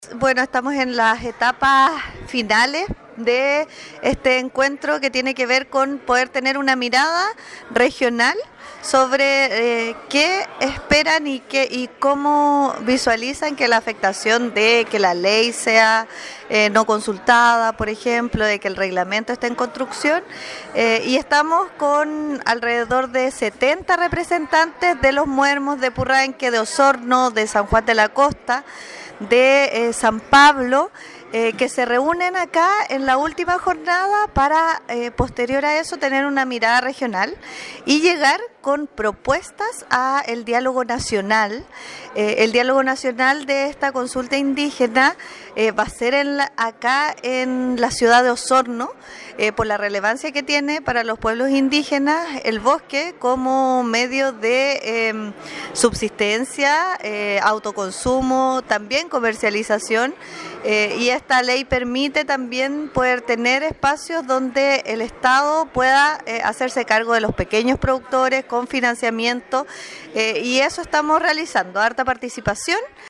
Así lo informó la Seremi de Energía, Liliana Alarcon: